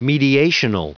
Prononciation du mot mediational en anglais (fichier audio)